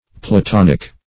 Platonic \Pla*ton"ic\, Platonical \Pla*ton"ic*al\, a. [L.